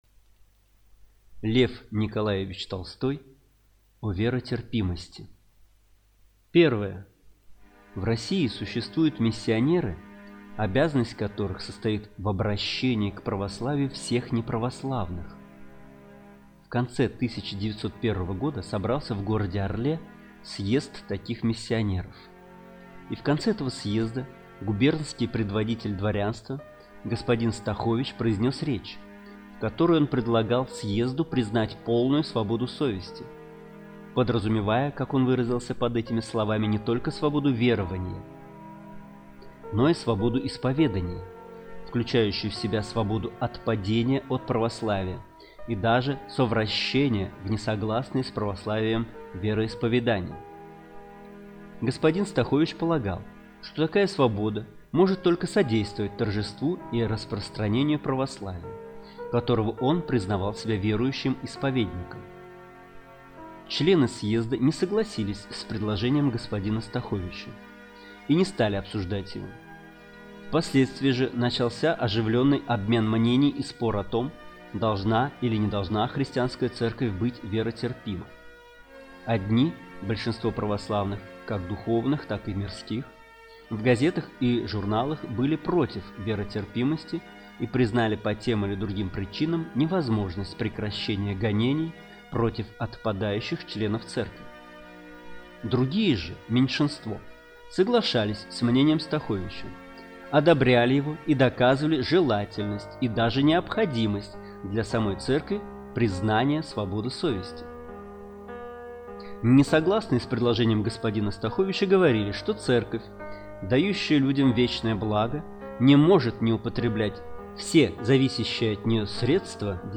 Аудиокнига О веротерпимости | Библиотека аудиокниг